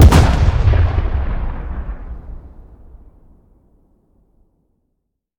weap_rpapa7_fire_plr_atmo_02.ogg